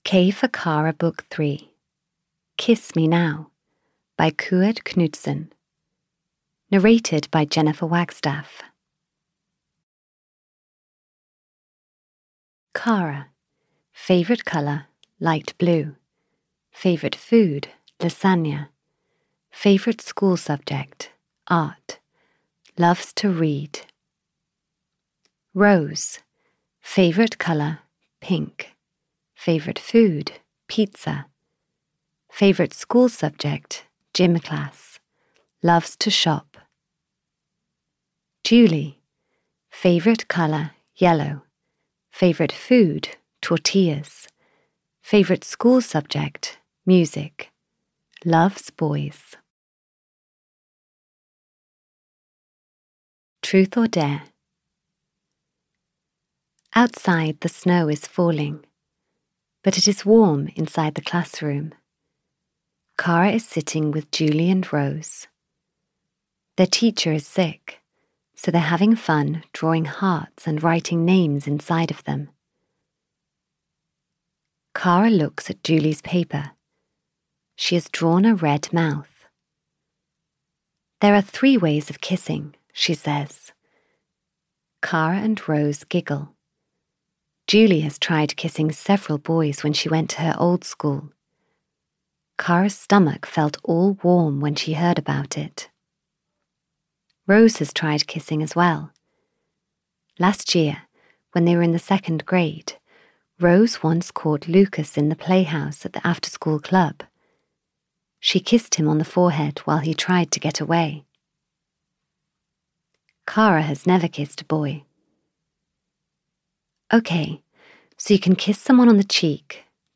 Ukázka z knihy
k-for-kara-3-kiss-me-now-en-audiokniha